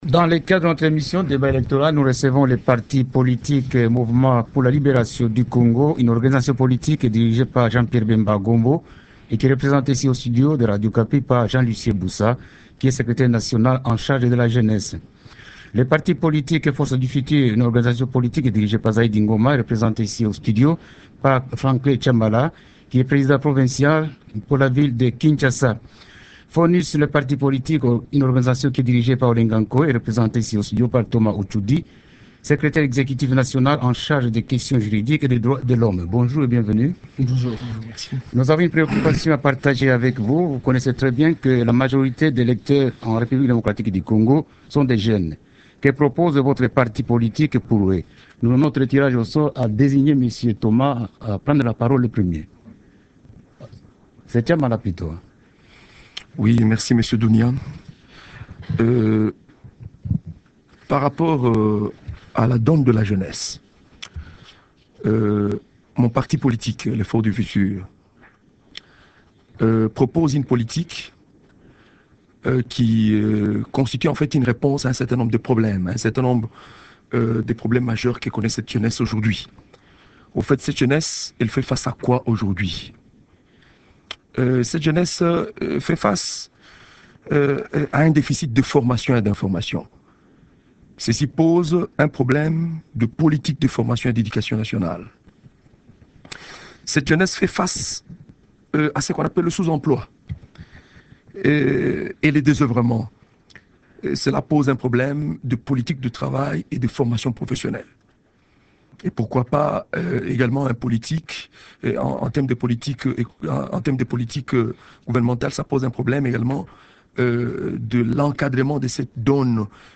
Le débat reçoit cette semaine :